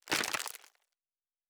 pgs/Assets/Audio/Sci-Fi Sounds/MISC/Plastic Foley 10.wav at master
Plastic Foley 10.wav